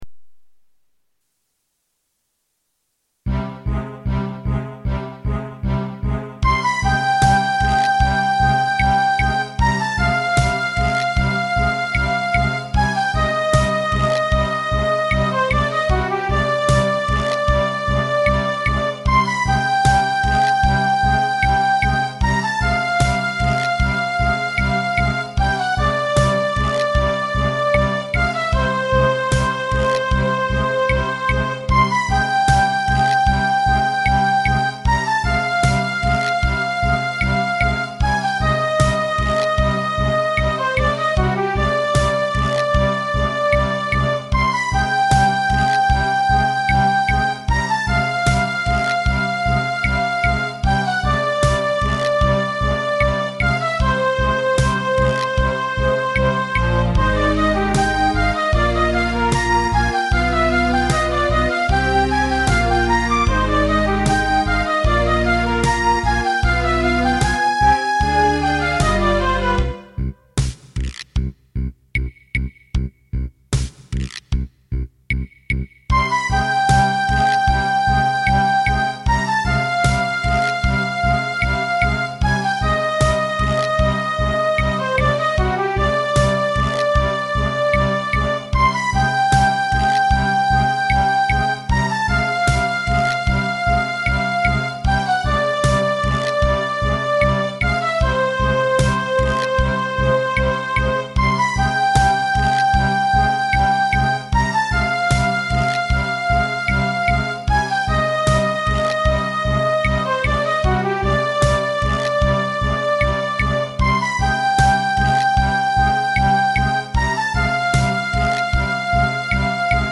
管理人が作ったMIDI集です